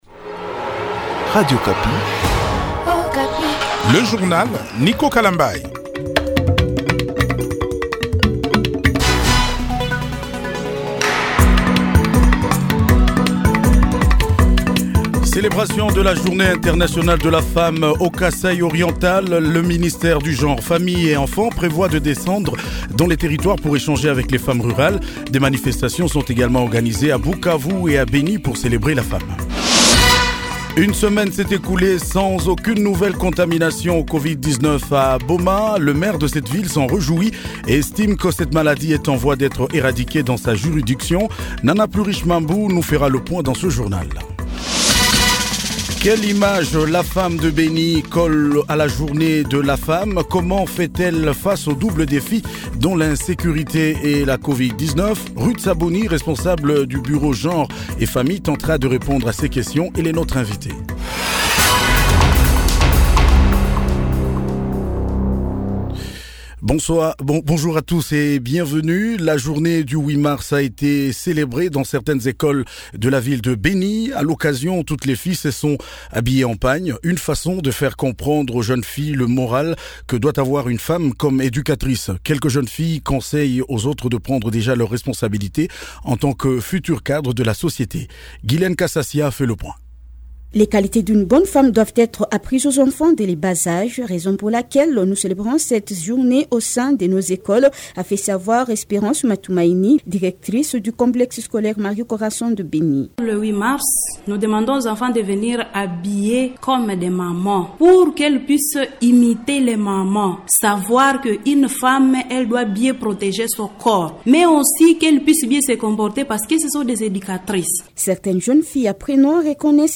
JOURNAL MIDI DU 08 MARS 2021